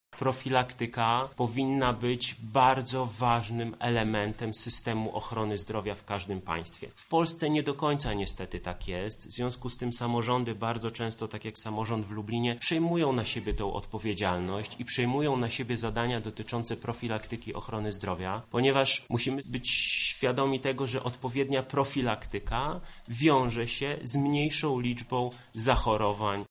Jednym z inicjatorów akcji był jeszcze jako miejski radny Michał Krawczyk, który mówi dlaczego jest ona istotna.